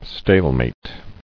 [stale·mate]